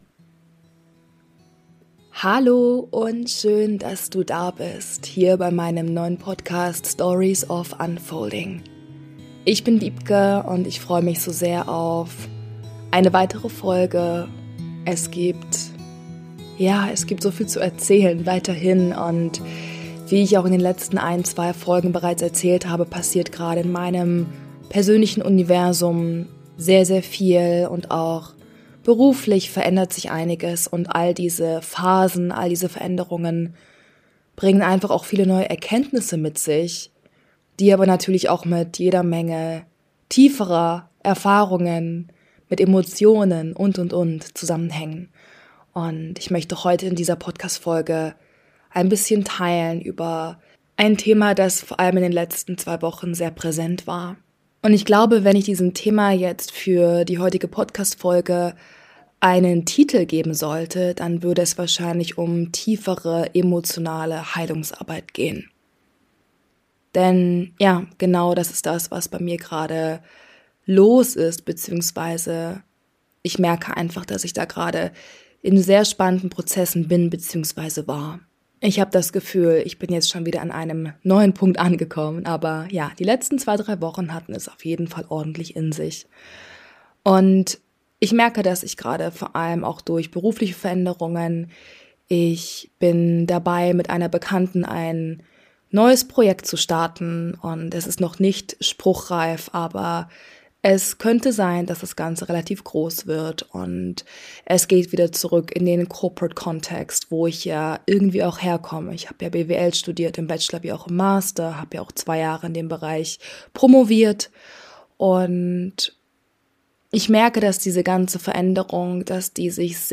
Ich selbst war ganz schön emotional beim Aufnehmen der Folge und hoffe sehr, dass sie dich berühren kann.